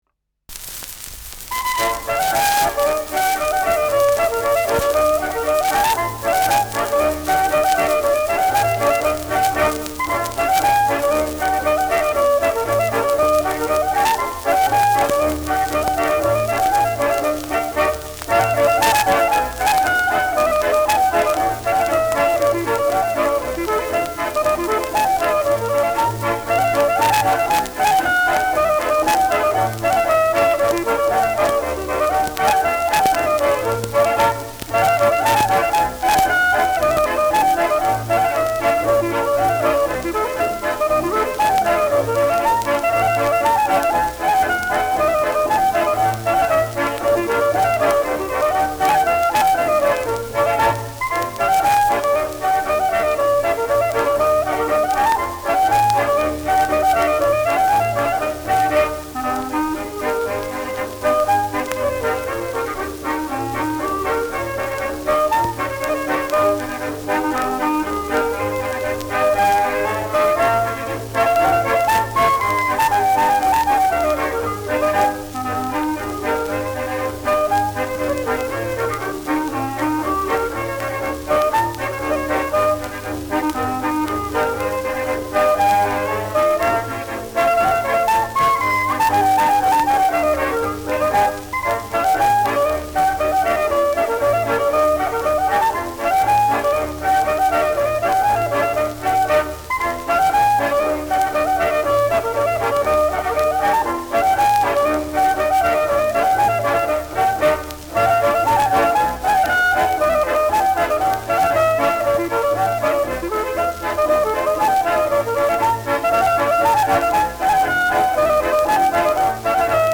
Schellackplatte
präsentes Knistern : leichtes Rauschen : leichtes „Schnarren“ : gelegentliches Knacken
Ländlerkapelle Echo vom St. Gotthard (Interpretation)
Ländlerkapelle* FVS-00018